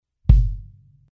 If you want a more dead sounding kick drum with less boom then you might want to smack that hole right in the middle of the kick.